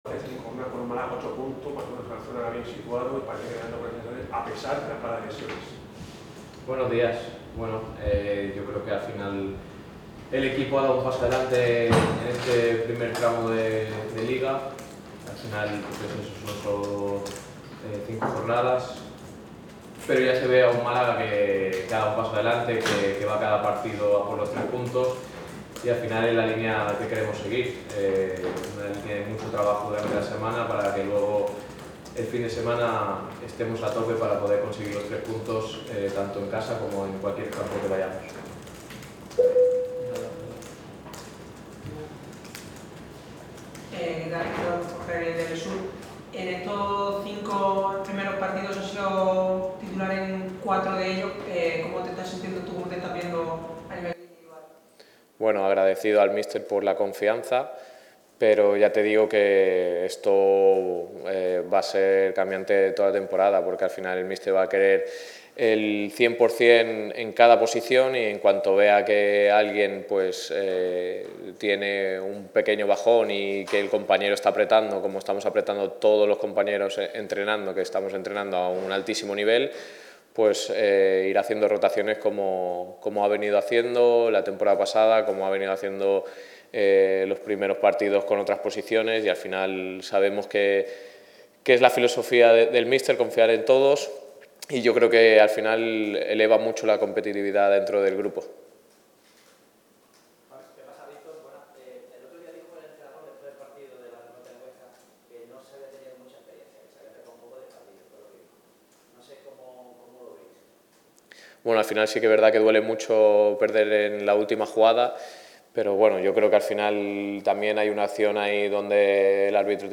El lateral malaguista ha dejado a un lado el carril zurdo de Martiricos para agarrar el micrófono de la sala de prensa. Le ha tocado hoy a él ejercer de portavoz y comparecer ante los medios de comunicación en una semana especial. Los boquerones afrontan estos días la primera derrota de la temporada en El Alcoraz (1-0) y el catalán analiza como ha sentado este resultado en el vestuario.